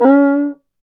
PRC CUICA0ER.wav